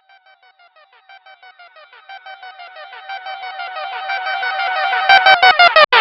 Transition [Run It Back].wav